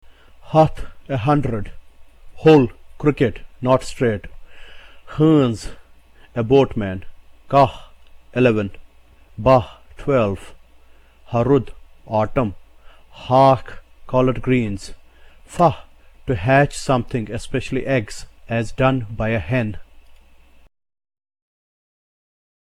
The consonant H is the aspirated sound of the letter H in the English word HAT.